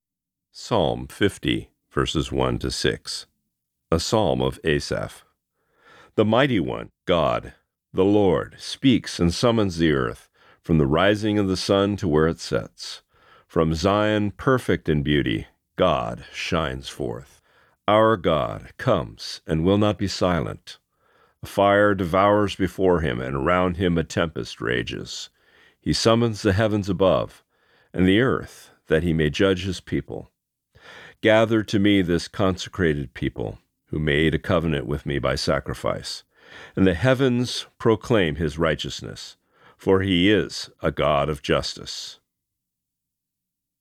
Reading: Psalm 50:1-6